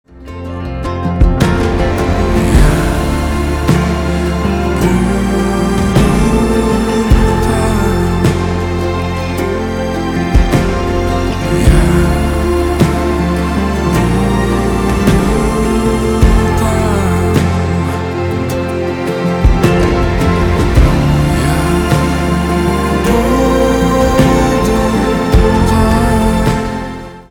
поп
чувственные
гитара , барабаны
скрипка